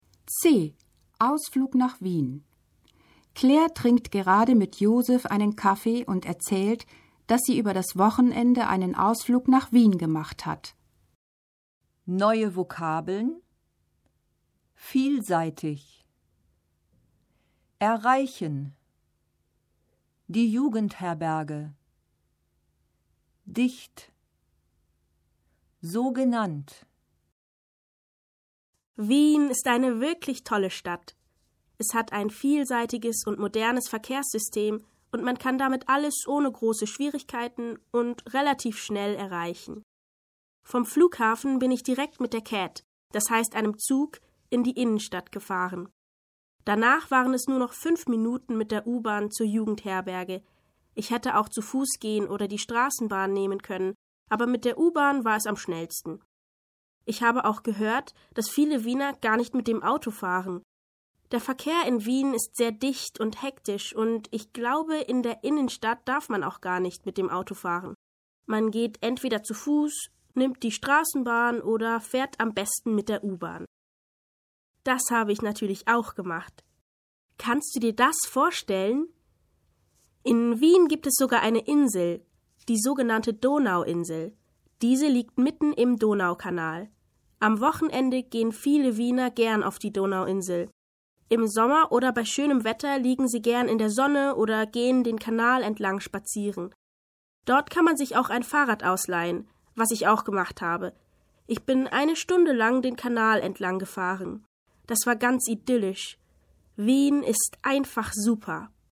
Rollenspiel: Am Fahrkartenschalter (1205.0K)